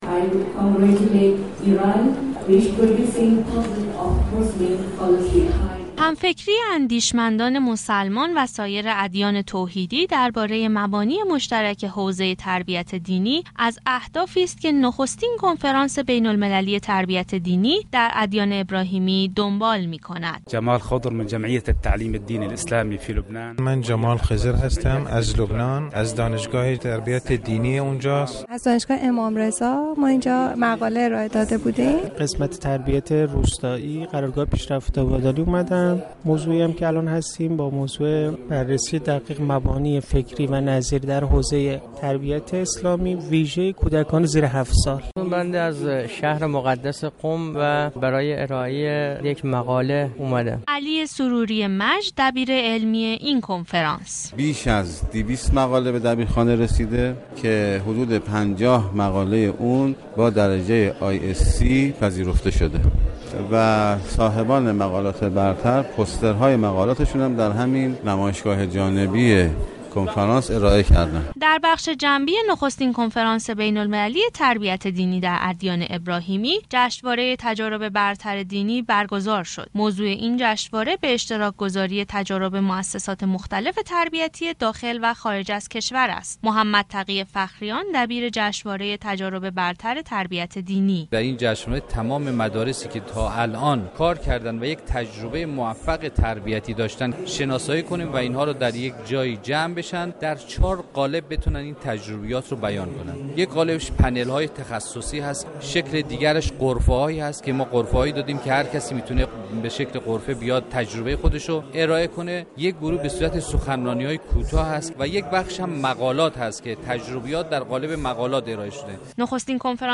نخستین كنفرانس بین‌المللی تربیت دینی در ادیان ابراهیمی در حرم مطهر رضوی آغاز به كار كرد